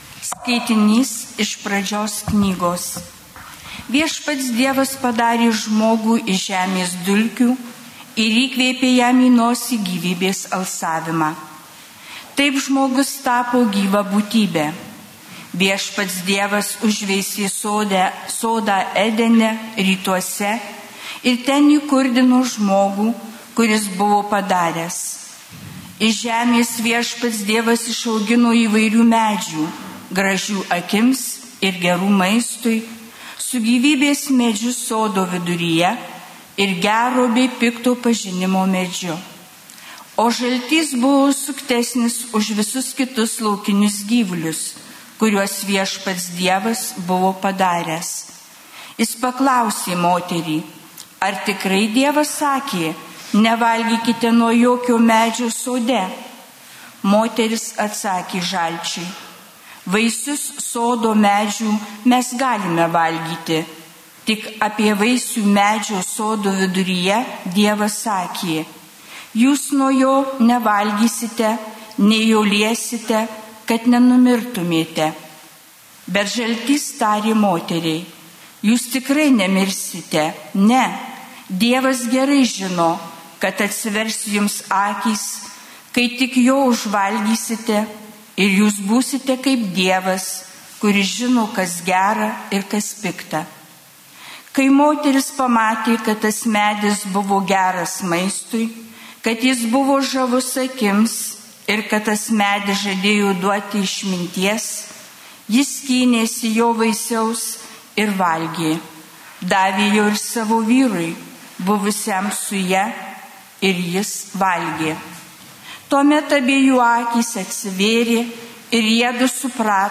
Šv. Mišios